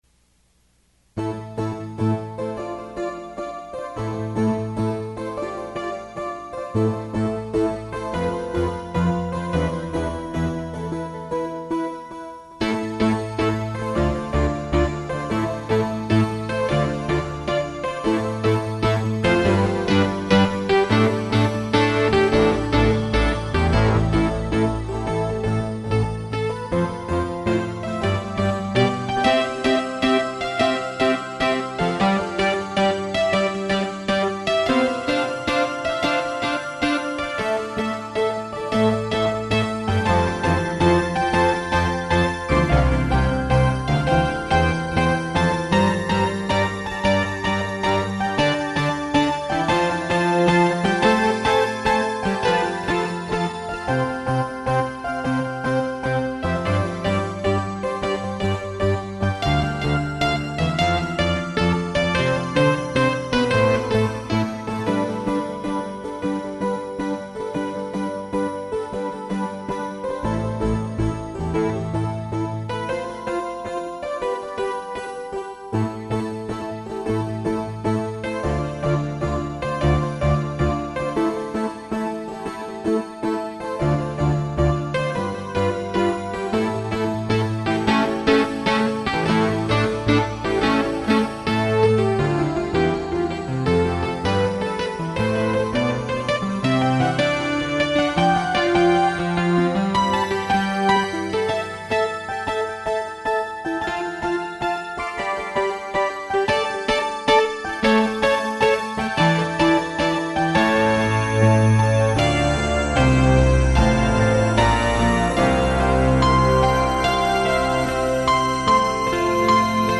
Aqui você poderá ouvir alguns sons de meus teclados, e também ter alguns arquivos MIDI com composições completas.
Essa seqüência foi gravada diretamente em uma fita cassete em 1998 e é o resultado de um momento de inspiração, sem nenhuma edição ou acréscimo. Assim, ignorem pequenas falhas. Somente foi usado JV-90 e S4 Plus na época.